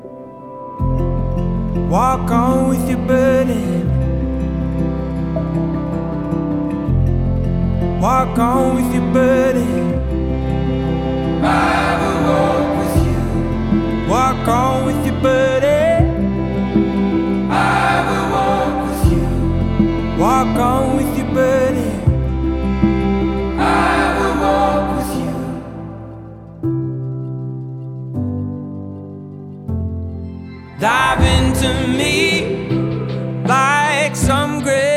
2024-02-16 Жанр: Альтернатива Длительность